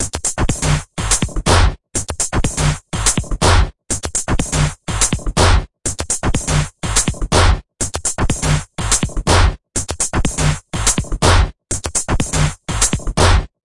节拍123bpm
描述：做音乐这个节拍加工的节拍循环
Tag: 电子 日本